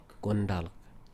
GOON-dulk
IPA [gʊndalk]